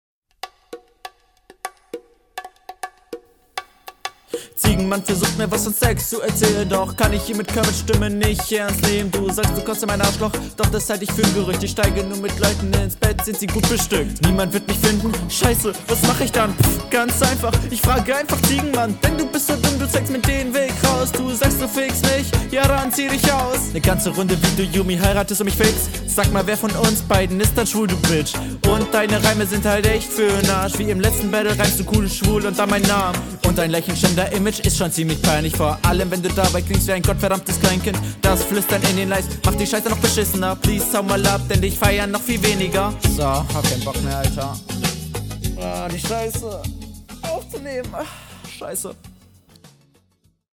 Lass den Doubletime du Knecht, verkackst voll.